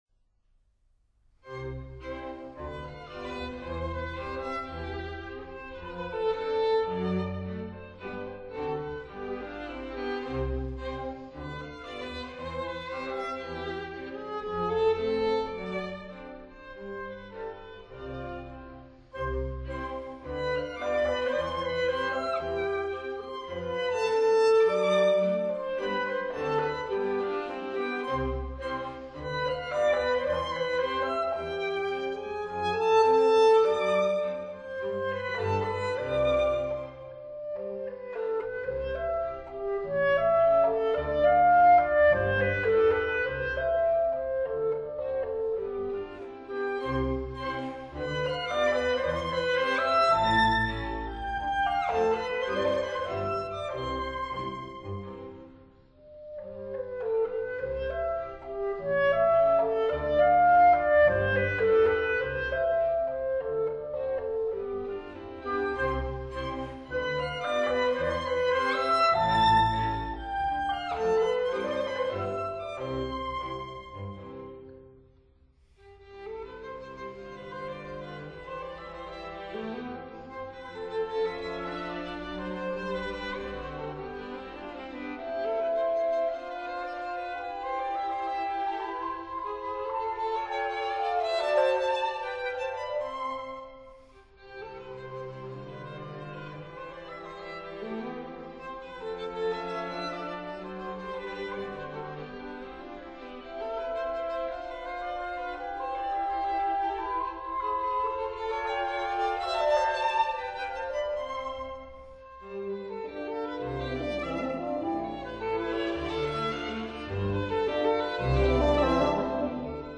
clarinet, bassoon, horn & strings
F major